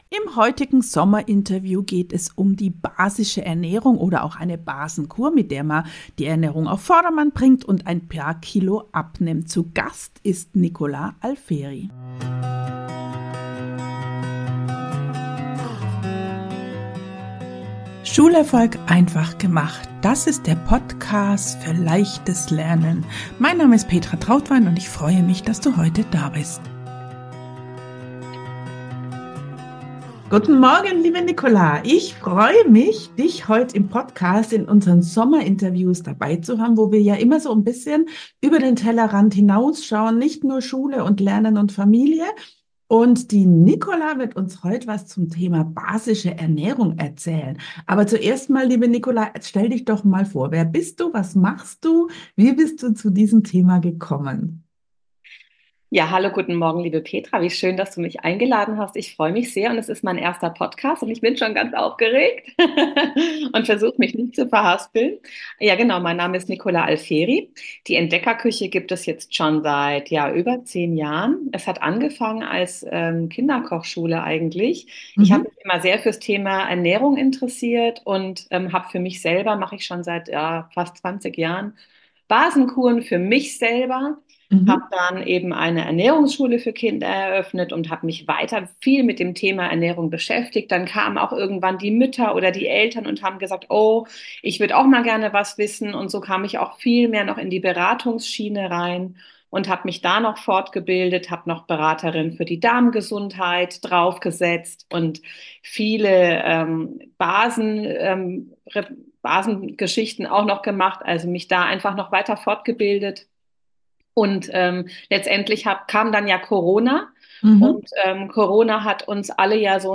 Beschreibung vor 1 Jahr Unser erstes Sommerinterview dreht sich um das Thema basische Ernährung und wie wir mit kleinen Anpassungen und leckeren Rezepten nicht nur uns selbst, sondern auch unseren Kindern etwas Gutes tun können - und das ganz einfach und mit viel Spaß anstatt komplizierte Diäten, Kalorienzählen oder Ernährungsumstellung.